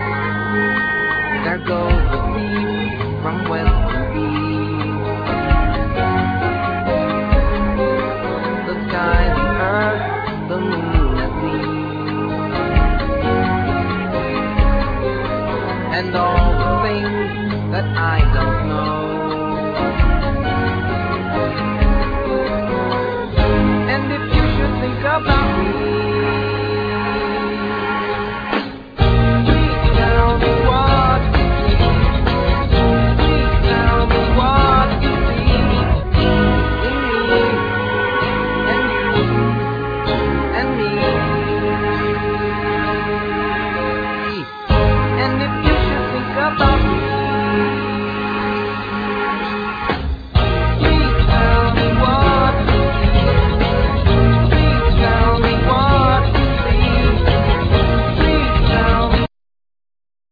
Drums,Bass,Keyboards,Vocal abuse
Guitar